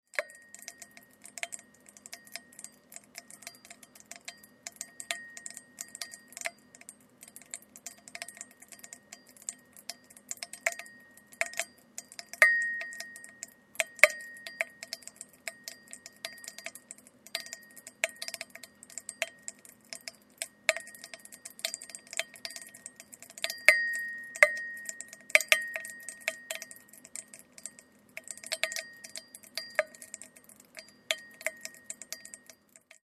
使い方 和菓子や洋菓子、アイスクリームや各種デザートへのトッピング  紅茶やコーヒー、ジュースなどの飲料に入れる （器と反応して※水琴窟のようなきれいな音色がする） ※水琴窟とは庭や茶室の外に水瓶などを伏せて埋め、上から水滴が落下して発する琴のような水音を楽しむもの ⚠弾けるザラメが勢いよく弾けて飛んでくることがあります。
チョコレートなどの洋菓子や和菓子に練り込む 水分の少ない和菓子・洋菓子に挟み込む 器の中で「弾けるザラメ」が はじける音をお聞きください。